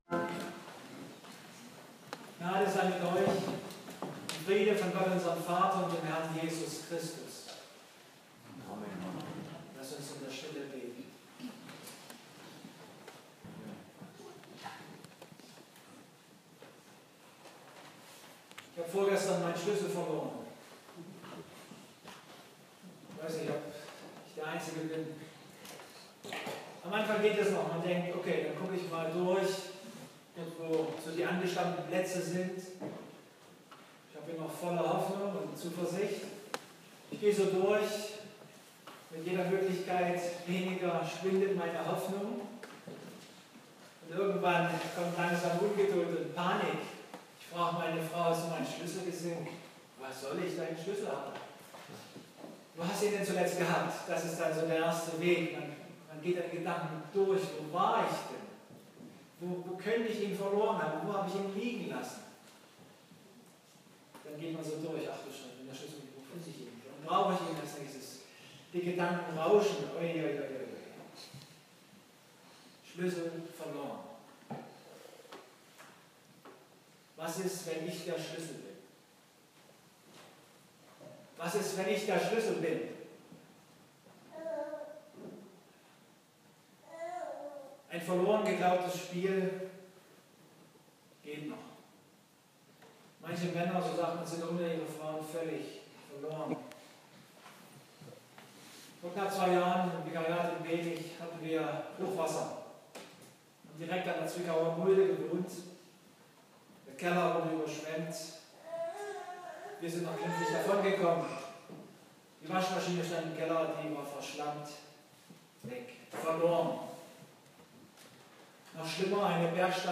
Lk 19,1-10 Gottesdienstart: Abendmahlsgottesdienst Ich habe vorgestern meinen Schlüssel verloren